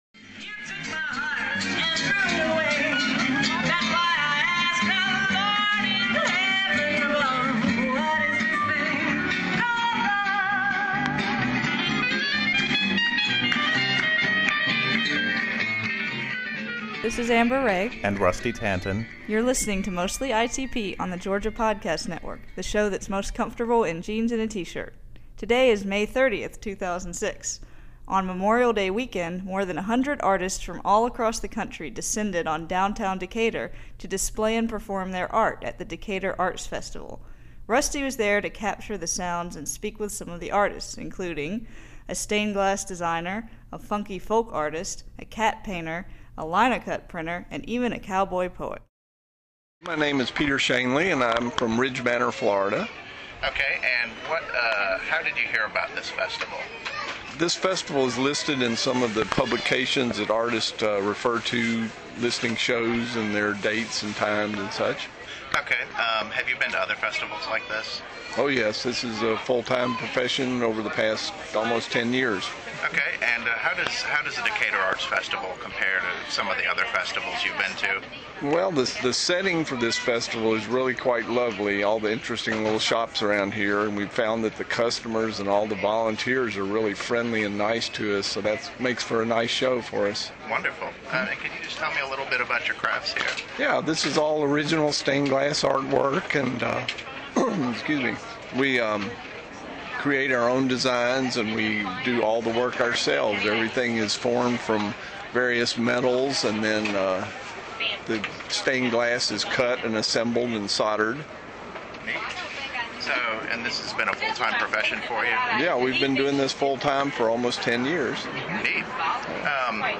Sounds and Interviews from the Decatur Arts Festival | Georgia Podcast Network